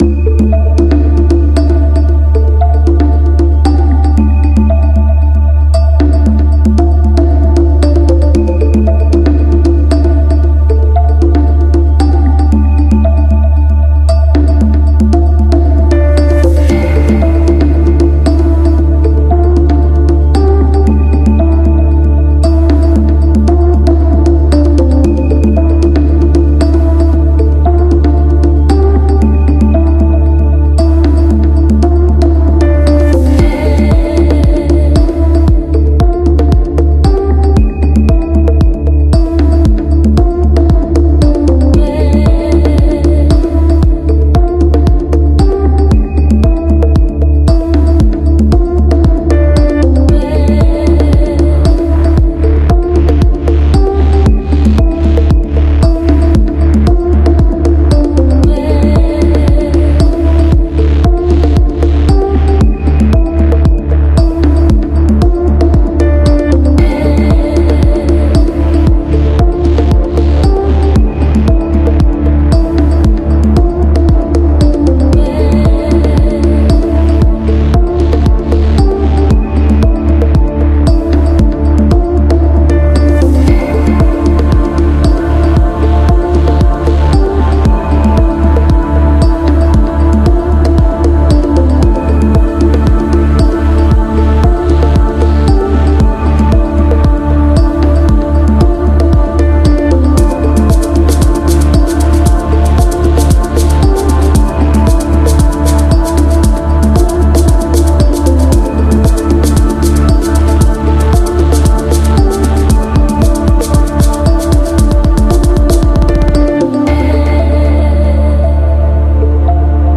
Genre: Progressive House